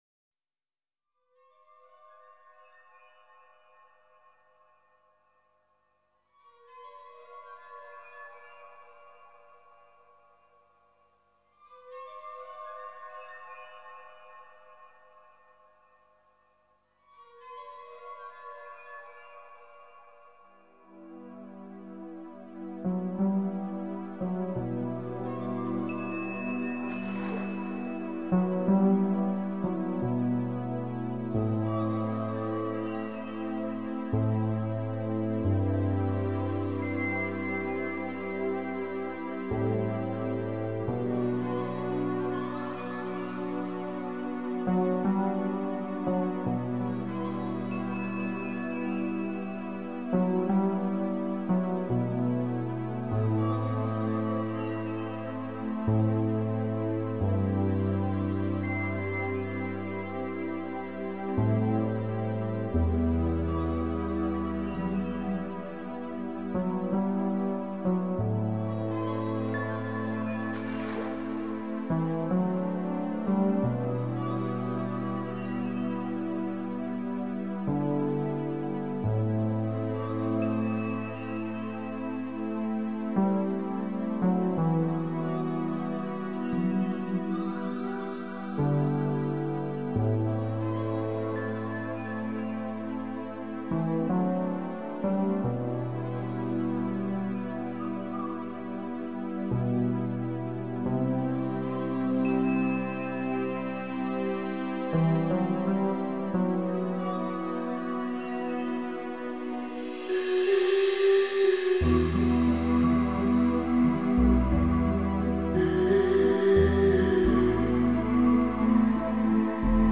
специальностью которой стал мягкий синтезаторный new age.